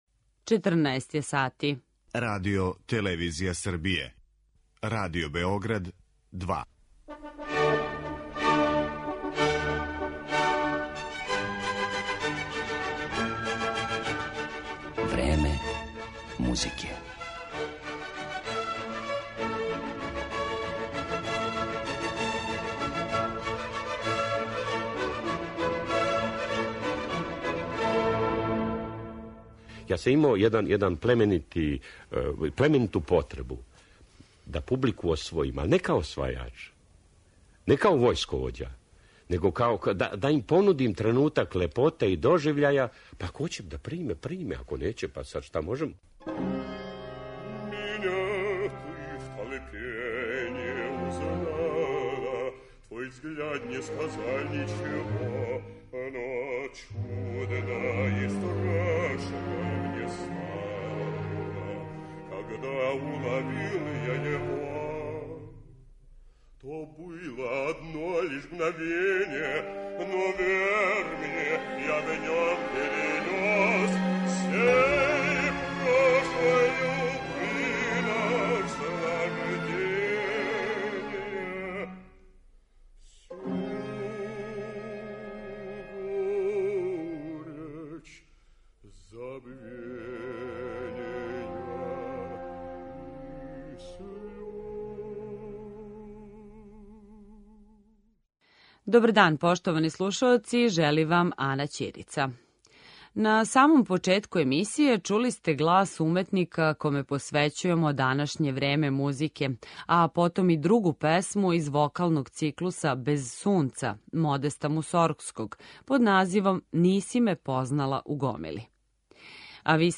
Тим поводом ћемо се подсетити неких његових интерпретација, али и промишљања из емисија које су сачуване у Звучном архиву Радио Београда.